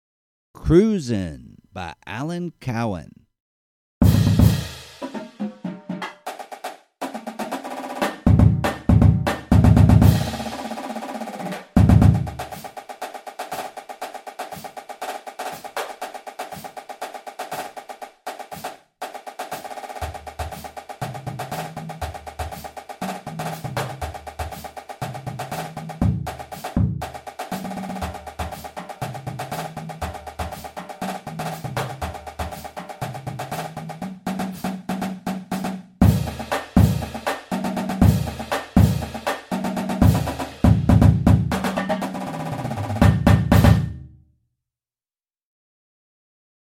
Voicing: Percussion Feature